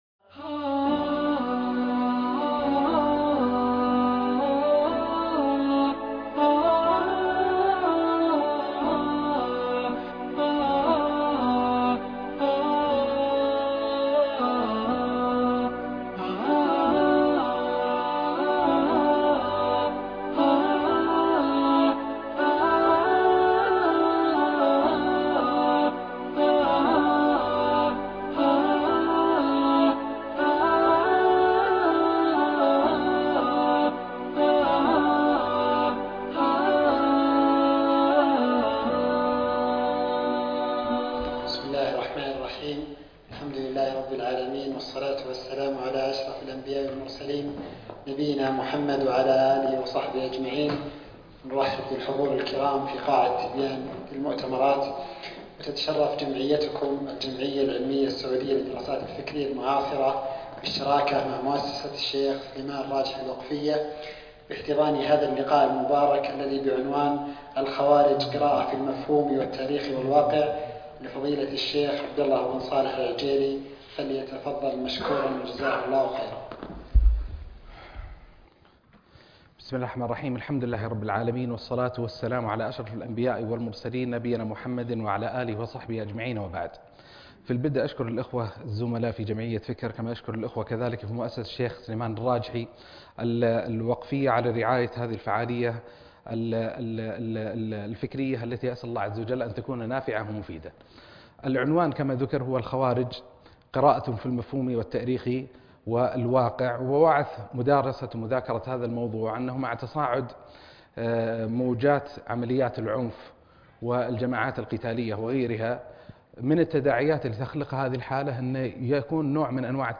محاضرة الخوارج وقراءة المفهوم والتاريخ الواقع